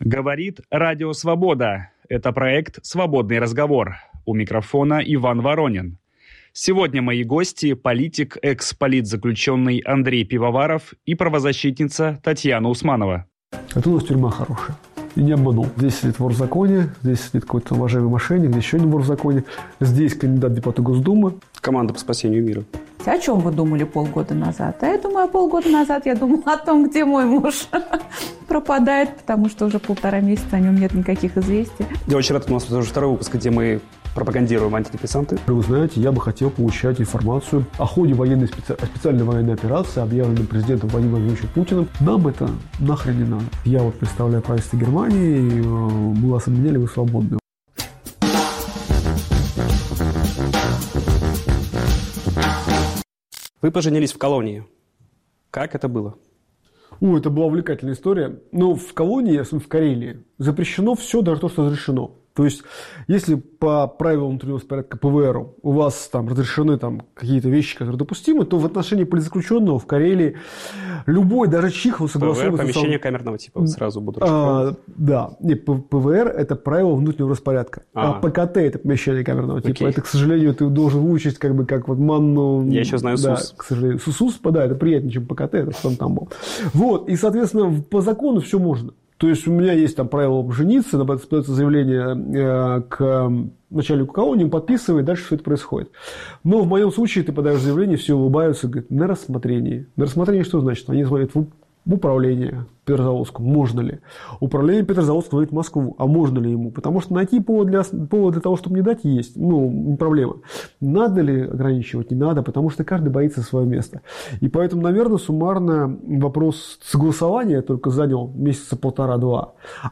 В интервью Радио Свобода